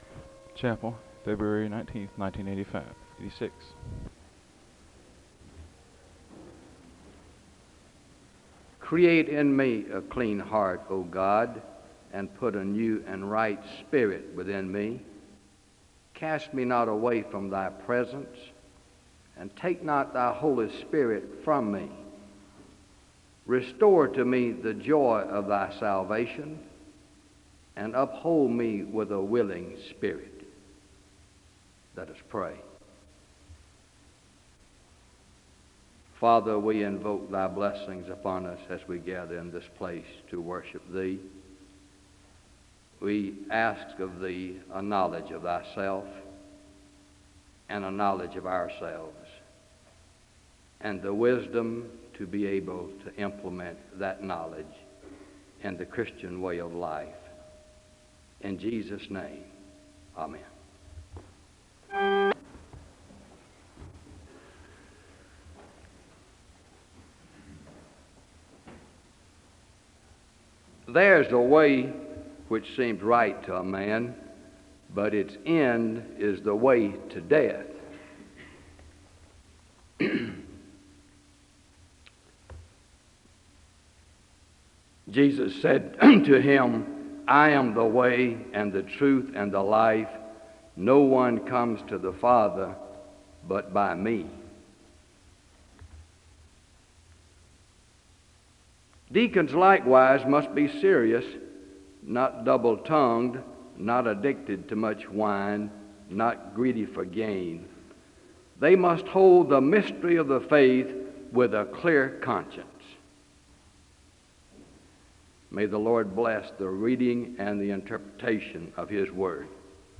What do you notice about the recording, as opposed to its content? The service begins with a Scripture reading and a moment of prayer (0:00-1:01).